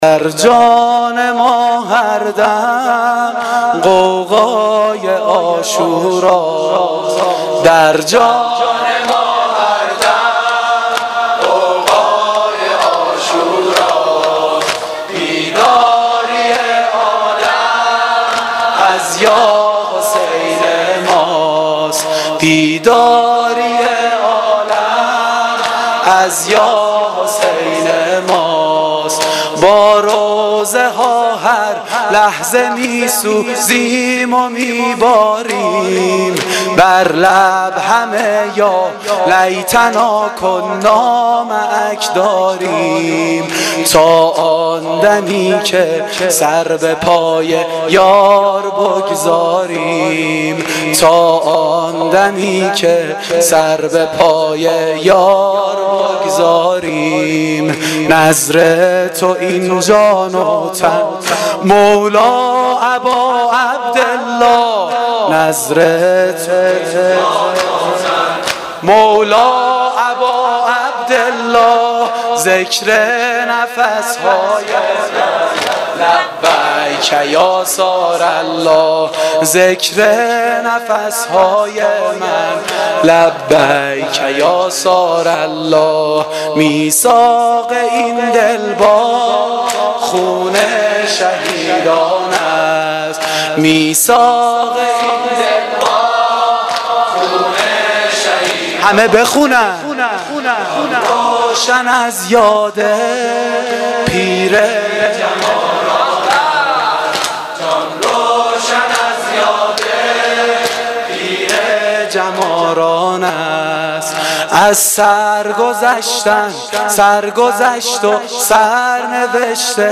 دم پایانی - شام غریبان حسینی